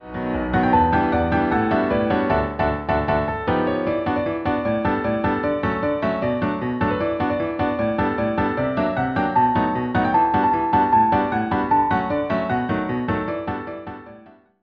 piano arr.